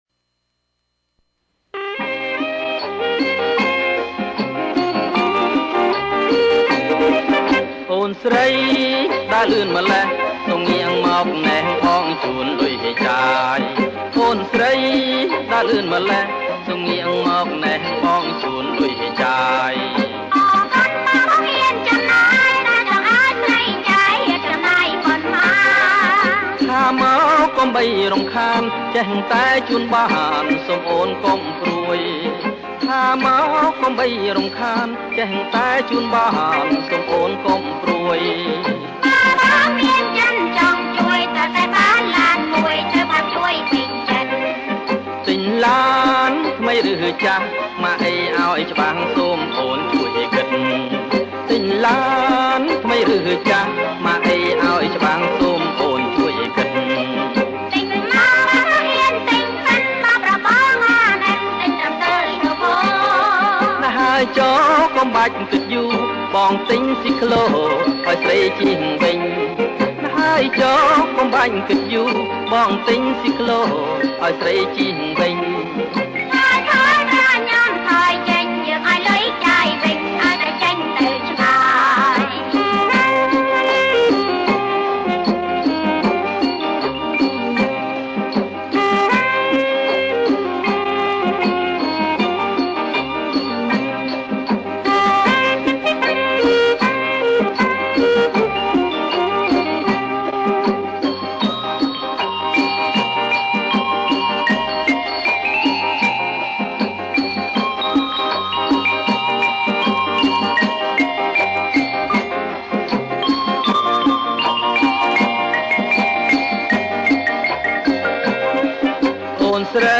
• ប្រគំជាចង្វាក់់ រាំវង់